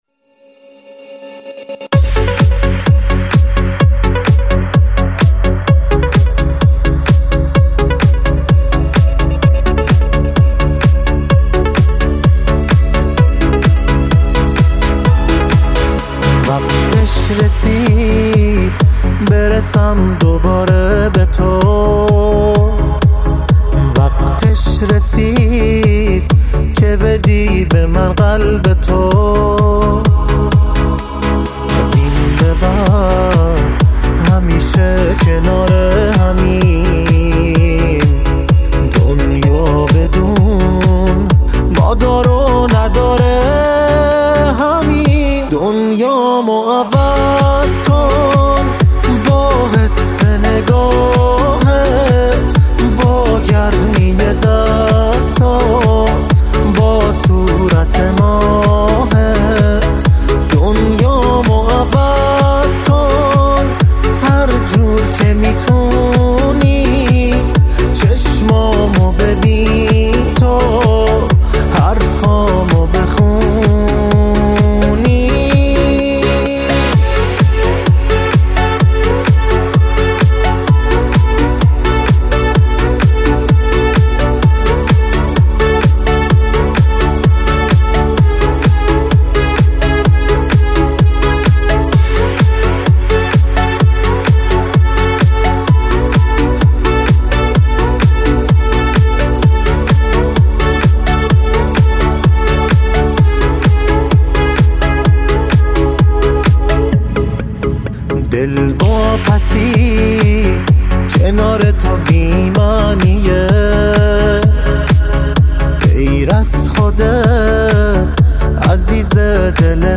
تك آهنگ ایرانی